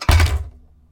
CraftSwitch.wav